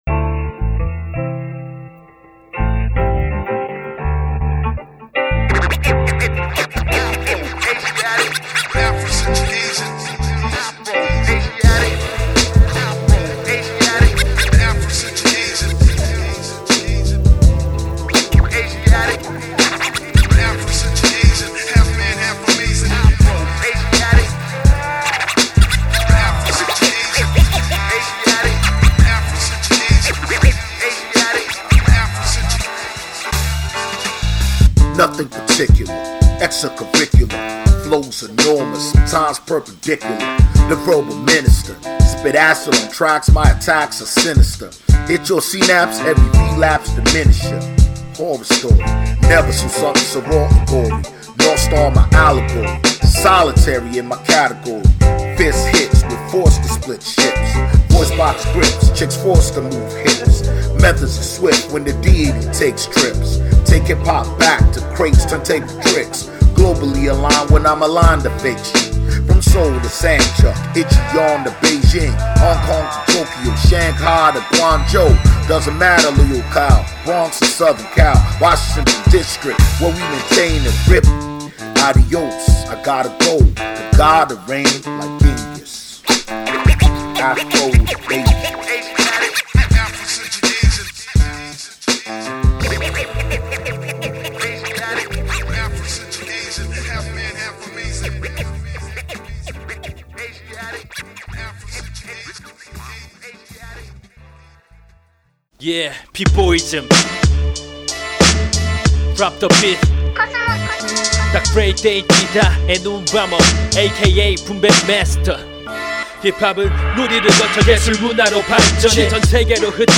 骨太なビートにキレの良いラップが乗るハードでコンシャスな仕上がり！